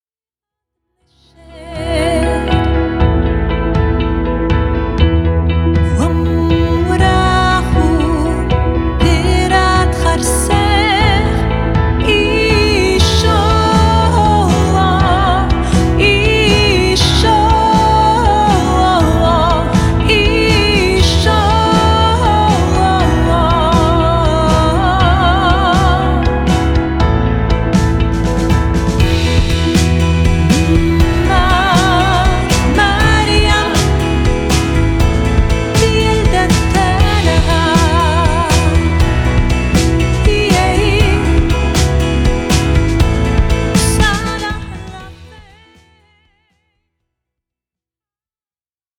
Dans un style pop saupoudré de musique du monde
pop version